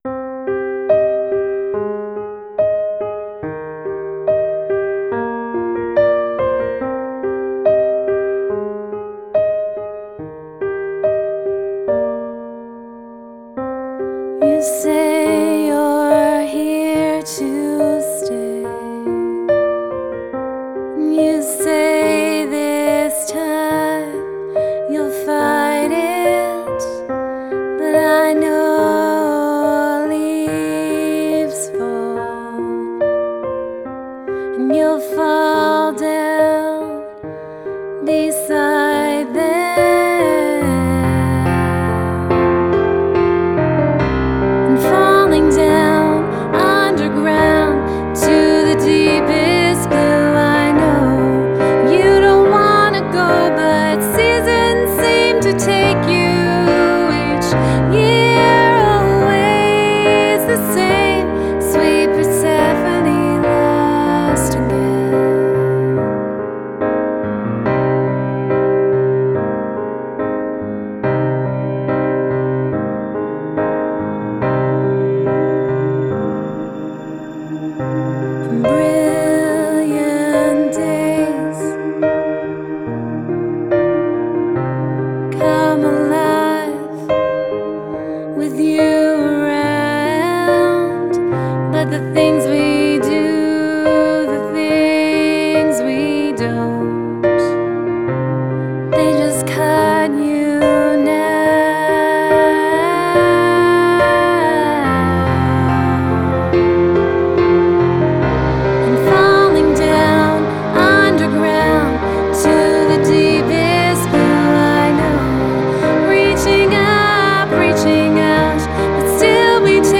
Full Mix (Vocal):
• Genre: Atmospheric Indie-Folk
• Mood: Melancholic, restrained, cinematic
• Instrumentation: Piano-led, intimate vocal